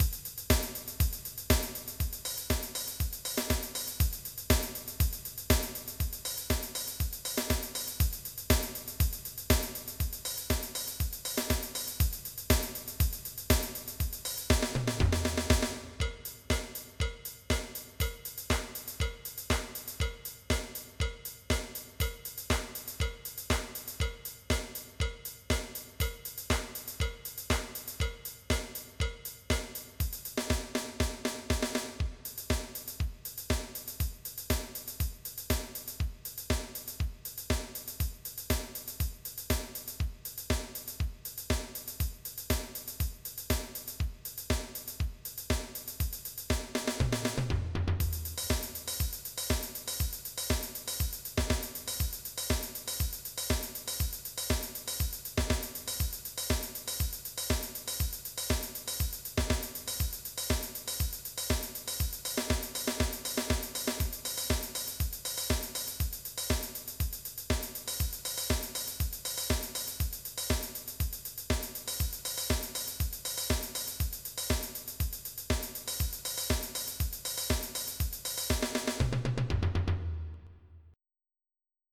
MIDI Music File
Type General MIDI
disco.mp3